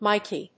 /ˈmaɪki(米国英語), ˈmaɪki:(英国英語)/